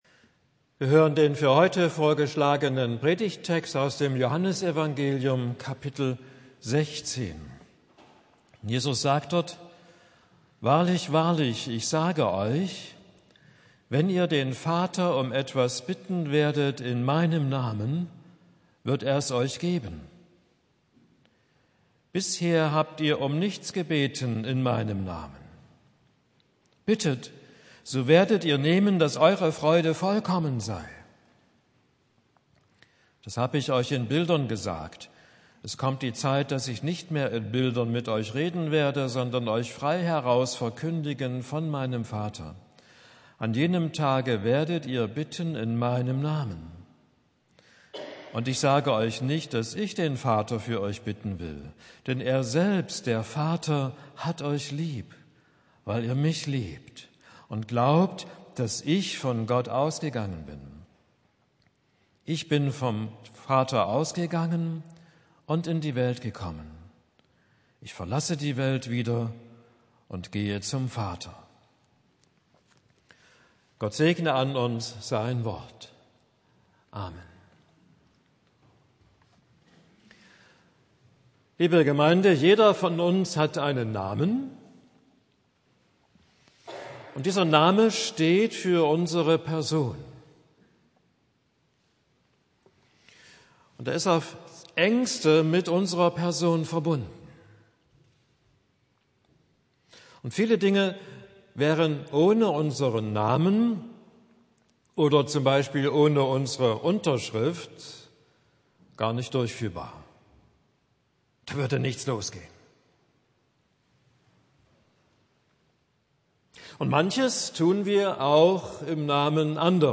Predigt für den Sonntag Rogate